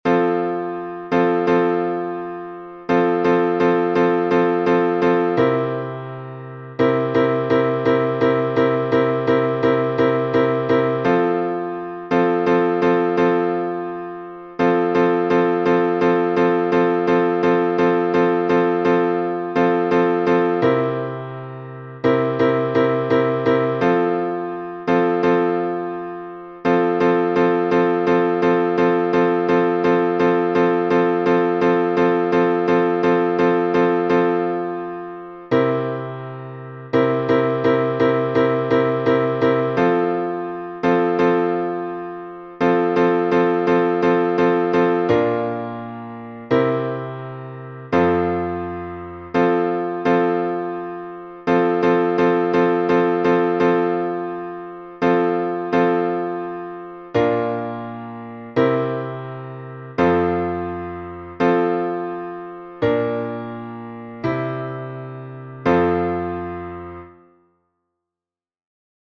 Обиходный напев